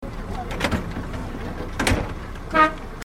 クラクション(3秒・64KB)
bxd30-horn.mp3